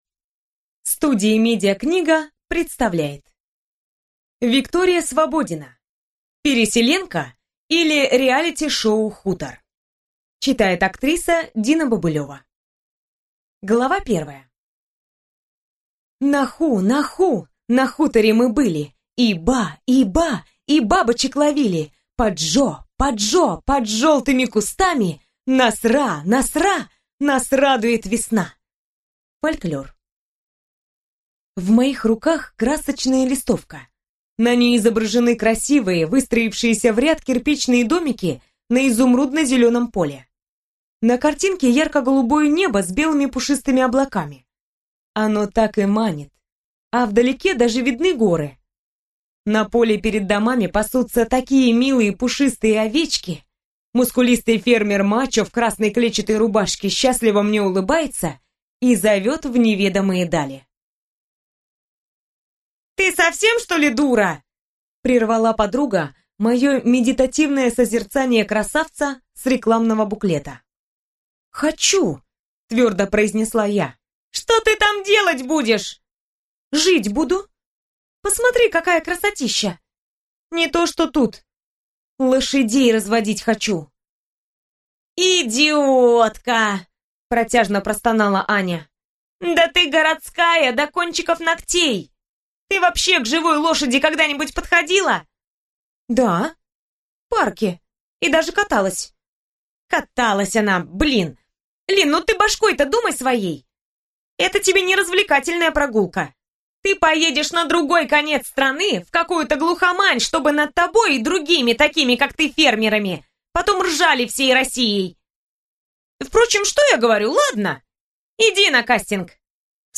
Аудиокнига Переселенка, или Реалити-шоу «Хутор» | Библиотека аудиокниг